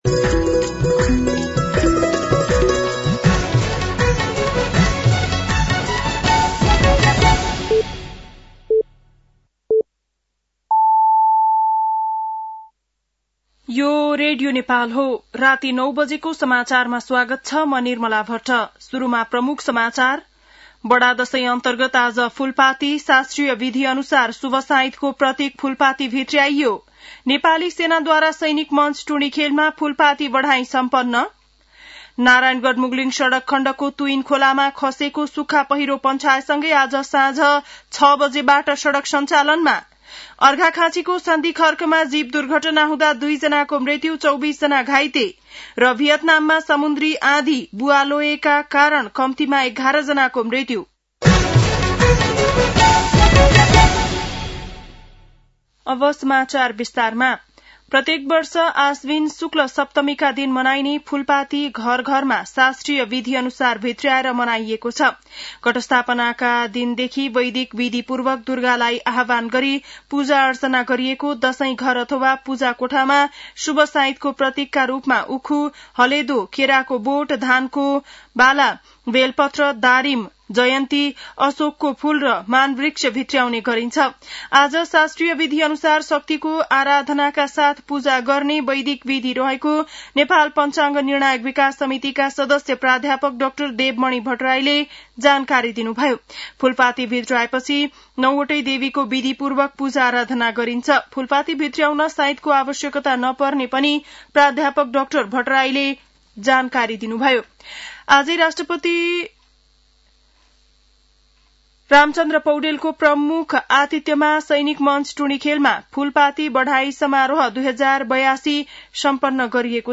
बिहान ९ बजेको नेपाली समाचार : १३ असोज , २०८२